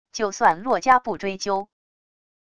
就算洛家不追究wav音频生成系统WAV Audio Player